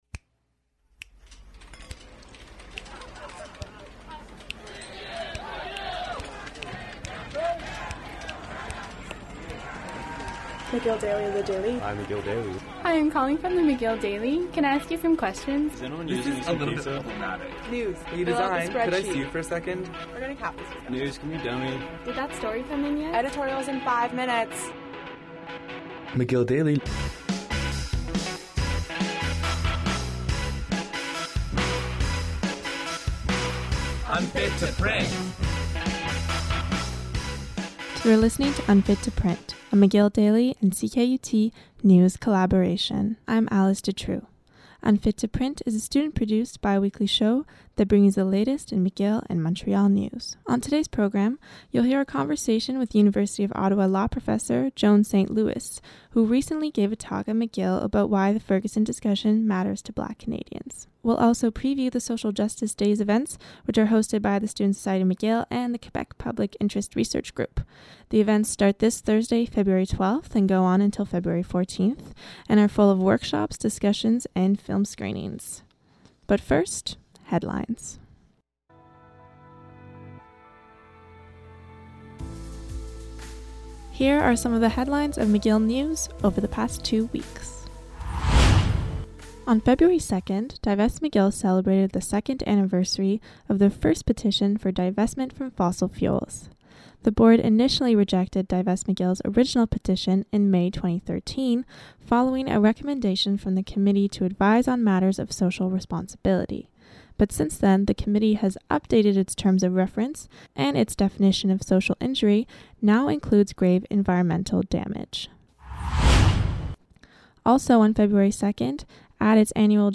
Unfit to Print is a bi-weekly news show produced by student contributors. It airs every other Tuesday on Off the Hour between 5-6pm on CKUT 90.3fm.